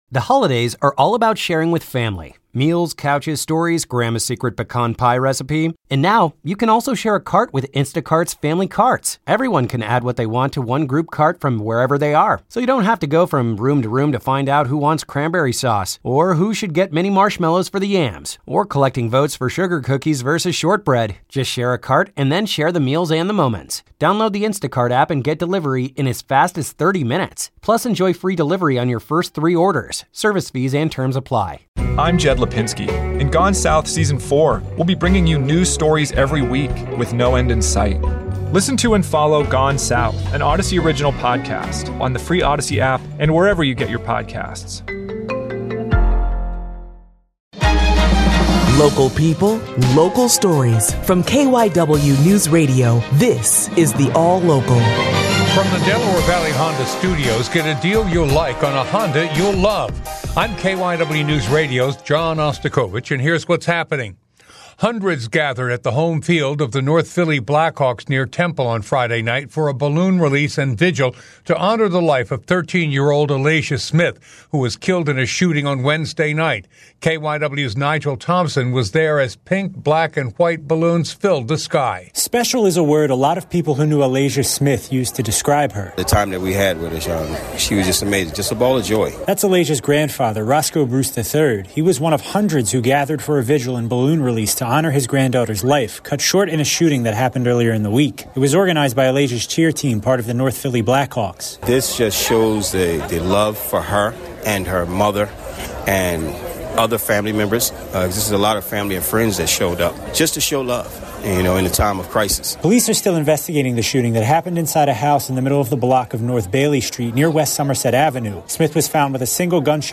The KYW Newsradio All-Local on Wednesday, Sep. 21, 2024 (morning edition):